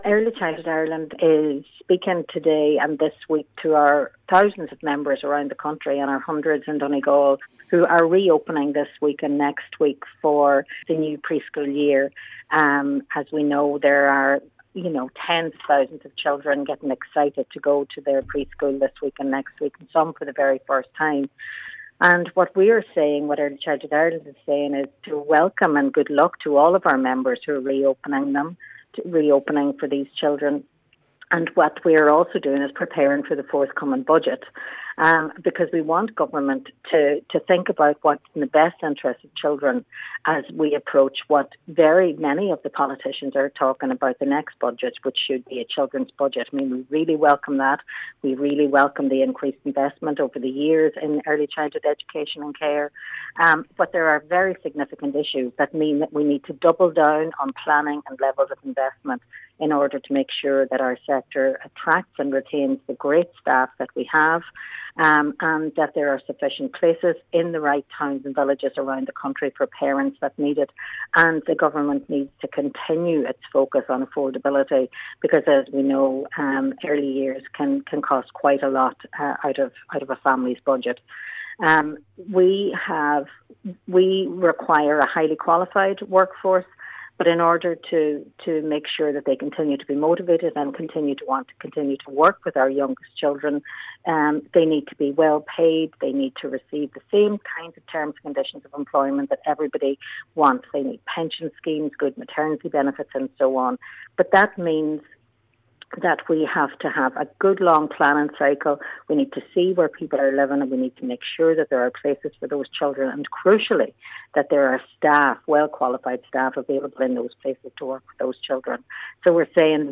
She says that must change as the summer break comes to an end…..